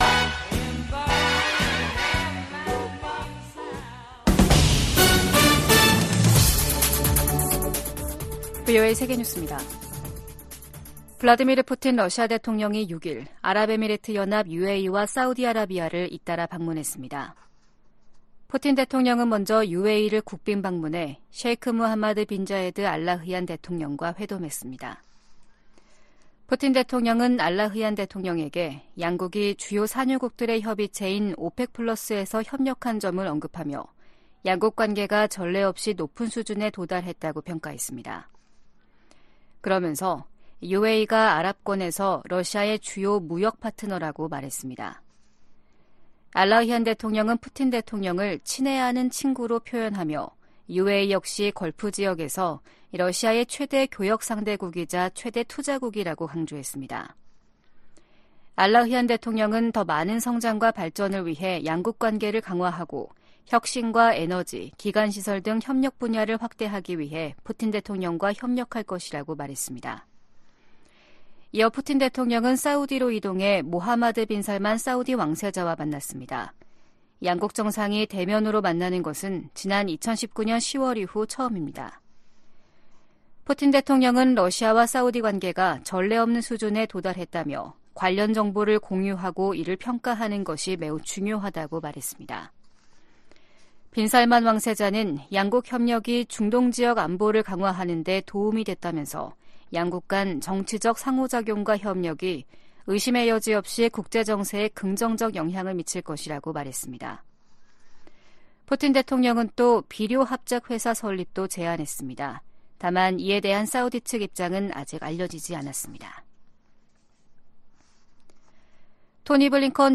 VOA 한국어 아침 뉴스 프로그램 '워싱턴 뉴스 광장' 2023년 12월 7일 방송입니다. 유엔총회가 북한 핵실험을 규탄하고 핵무기 폐기를 촉구하는 내용을 담은 결의 3건을 채택했습니다. 유럽연합(EU)이 북한과 중국, 러시아 등 전 세계의 심각한 인권 침해 책임자와 기관에 제재를 3년 연장했습니다. 북한 지도자가 딸 주애를 계속 부각하는 것은 세습 의지를 과시하기 위해 서두르고 있다는 방증이라고 한국 고위관리가 지적했습니다.